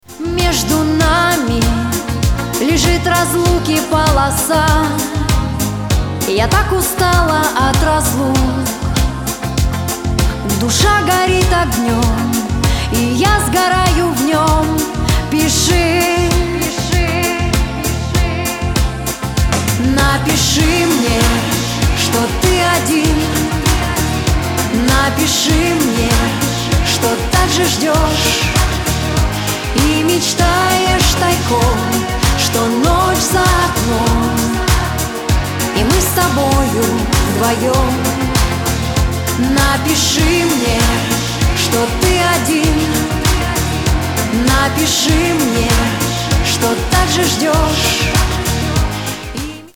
русский шансон